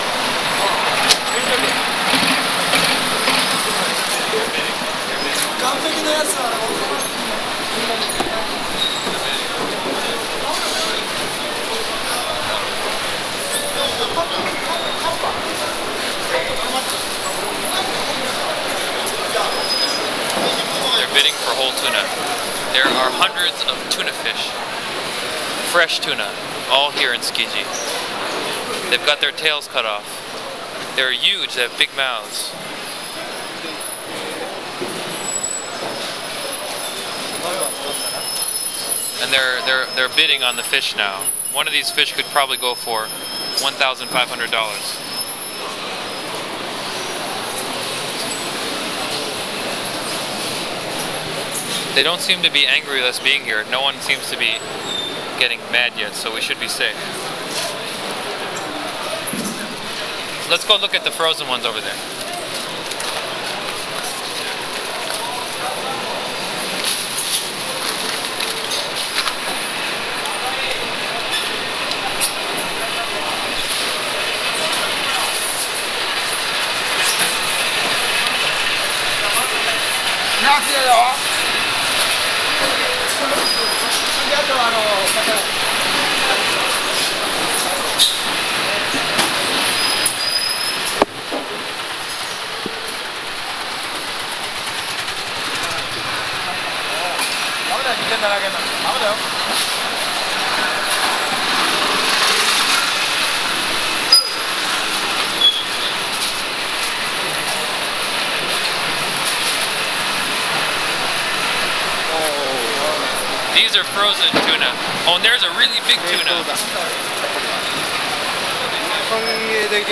Page 6 of [A Digital Walk Through Tsukiji Fish Market]